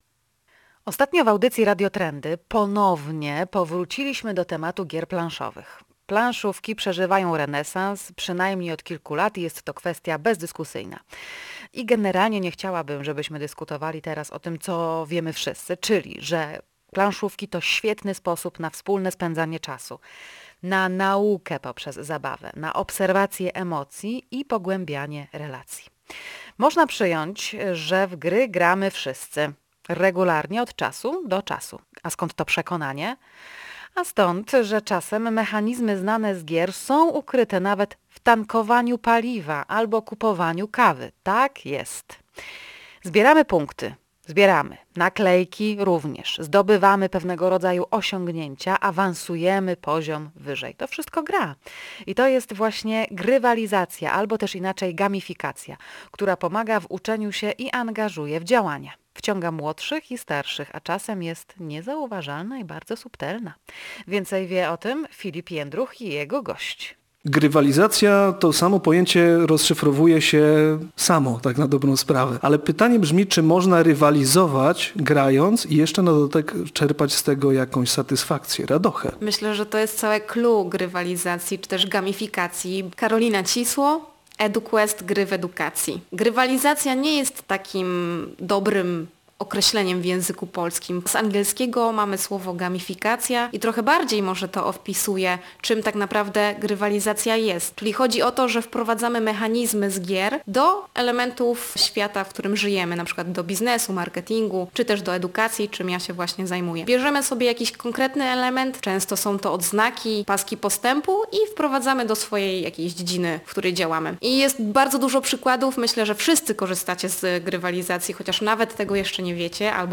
W materiale usłyszycie rozmowę